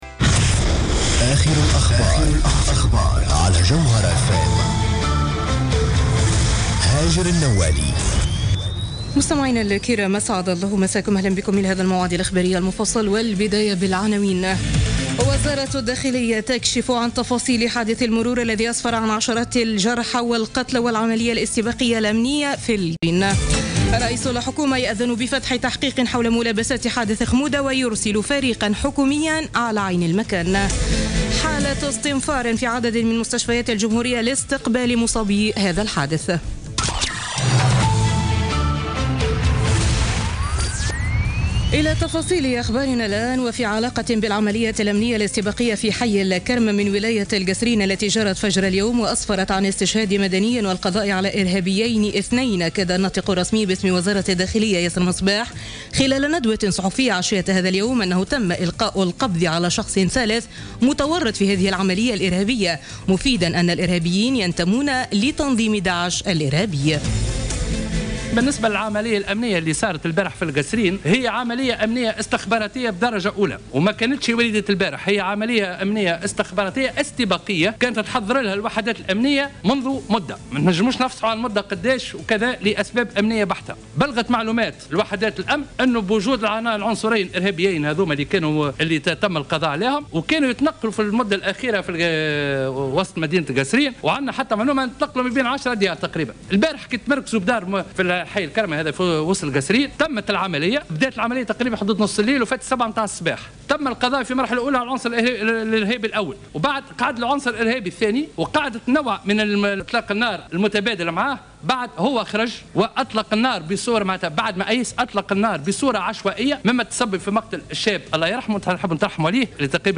نشرة أخبار السابعة مساء ليوم الأربعاء 31 أوت 2016